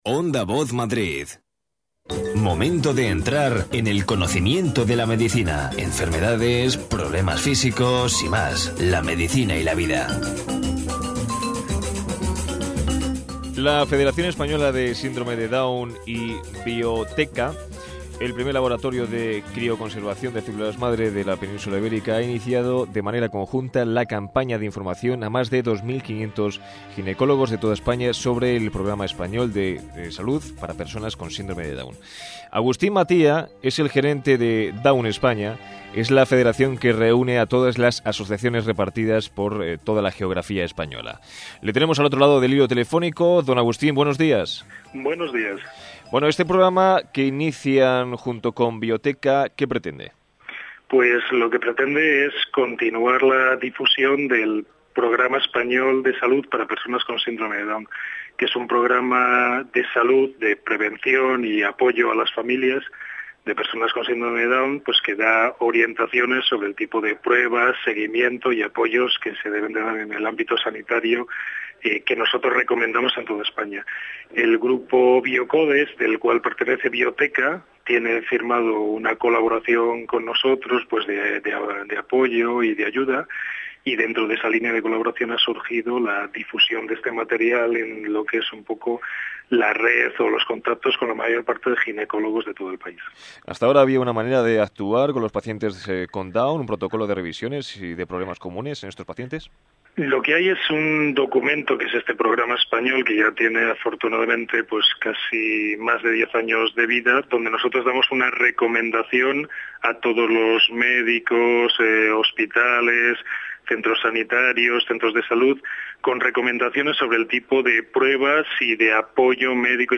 12_1_entrevista_0.mp3